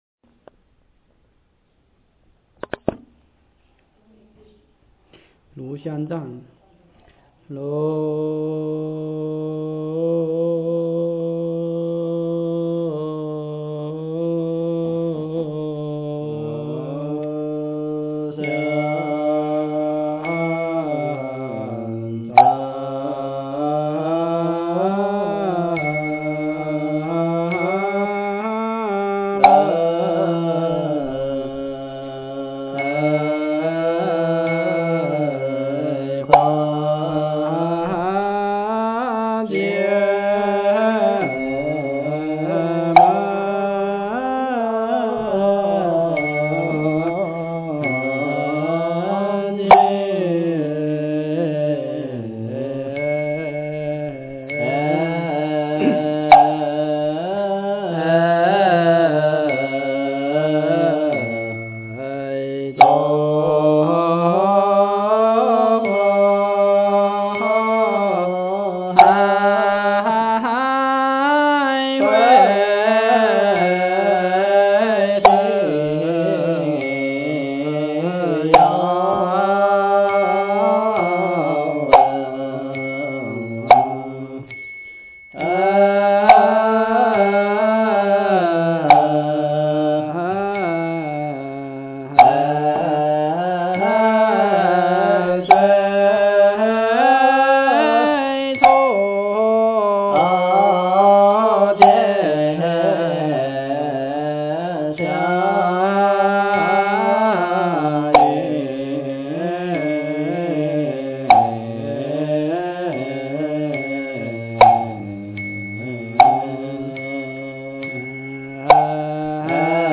经忏
佛音 经忏 佛教音乐 返回列表 上一篇： 晚课--万佛城 下一篇： 六字大明咒--心定和尚 相关文章 《妙法莲华经》随喜功德品第十八--佚名 《妙法莲华经》随喜功德品第十八--佚名...